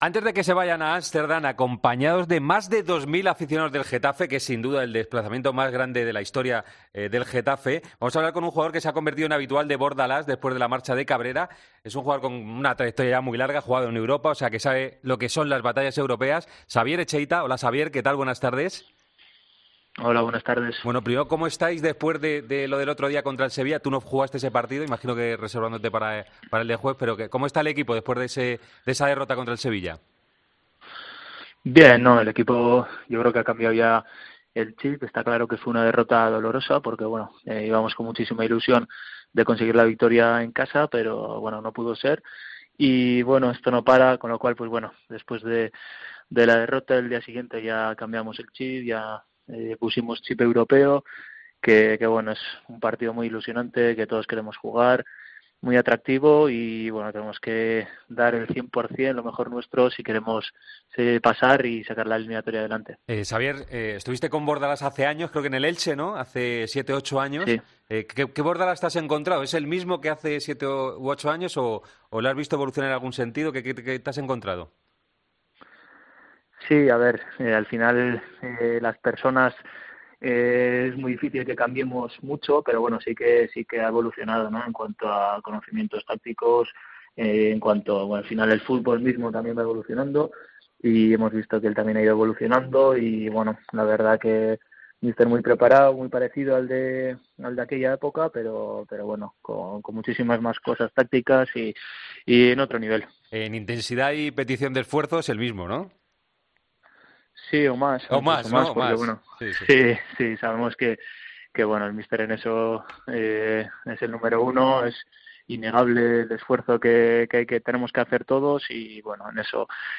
El jugador del Getafe ha atendido a Deportes COPE antes de viajar a Amsterdam: "El míster es el número uno en pedirnos esfuerzo a los jugadores"